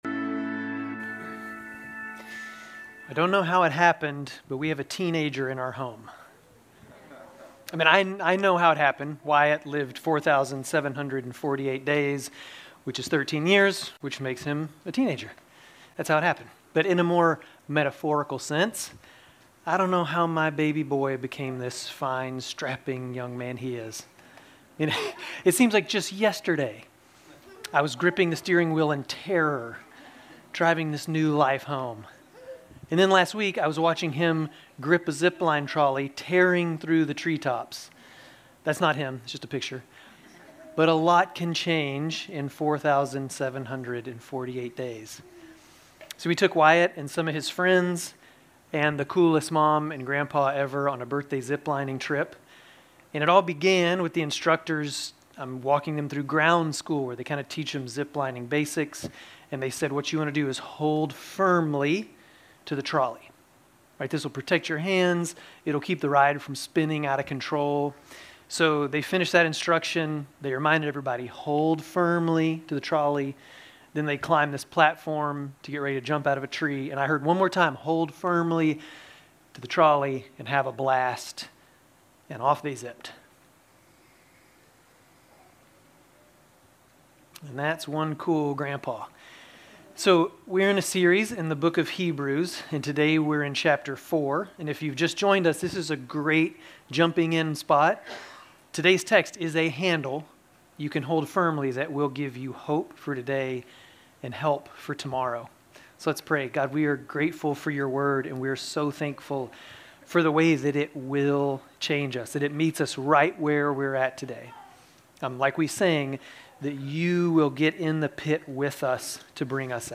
Grace Community Church Dover Campus Sermons 10_19 Dover Campus Oct 20 2025 | 00:29:03 1x 00:00 / 00:29:03 Subscribe Share RSS Feed Share Link Embed